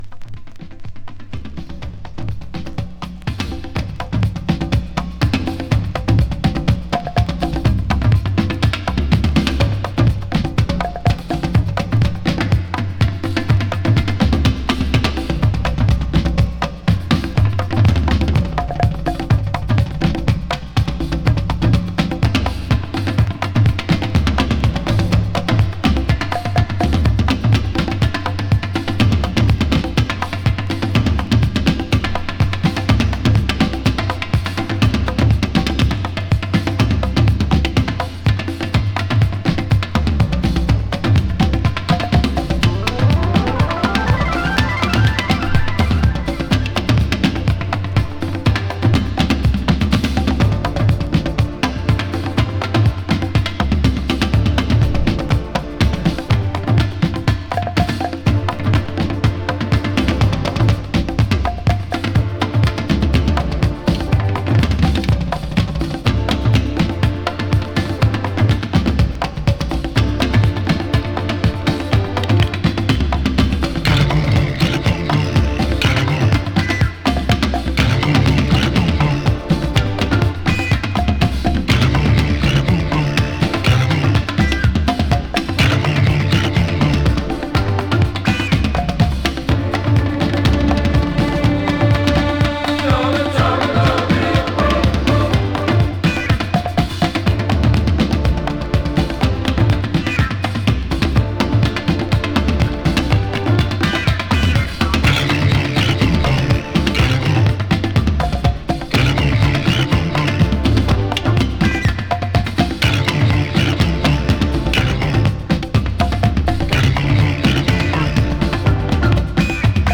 AFRO DISCO！
スモーキーなアフロ・ディスコ